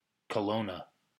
Kelowna (/kəˈlnə/
EN-CA-Kelowna.ogg.mp3